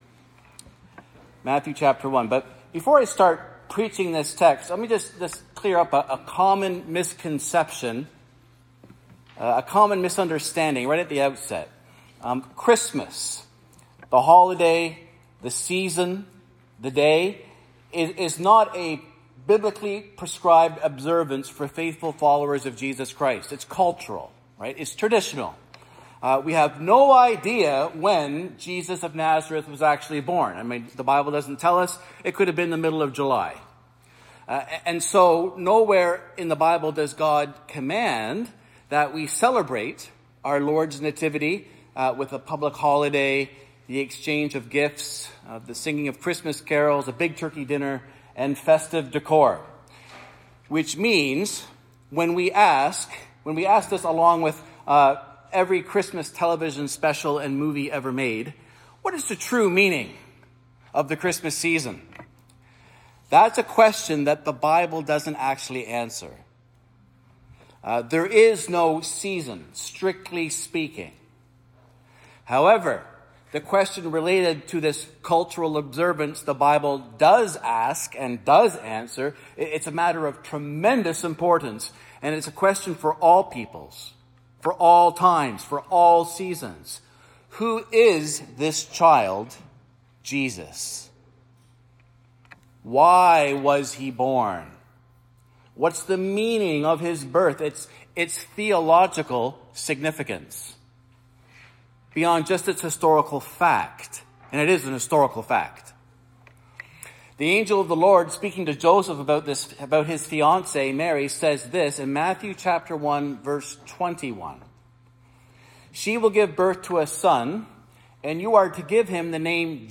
… continue reading 1 True Light Play Pause 4d ago Play Pause Play later Play later Lists Like Liked — A short sermon about the purpose of Jesus' coming.